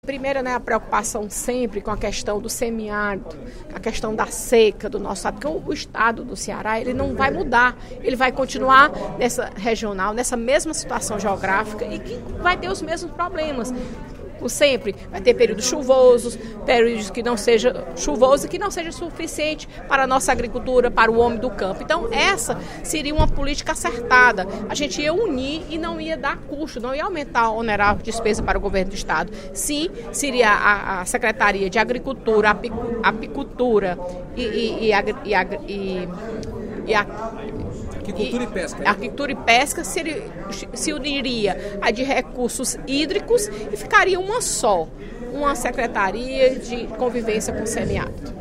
Durante o primeiro expediente da sessão plenária desta terça-feira (24/02), a deputada Fernanda Pessoa (PR) criticou a reforma administrativa do Governo do Estado, encaminhada na última semana à Assembleia Legislativa. De acordo com a parlamentar, a mudança não contempla a criação de uma Secretaria de Políticas de Convivência com a Seca, em substituição à proposta de criação da Secretaria de Agricultura, Aquicultura e Pesca.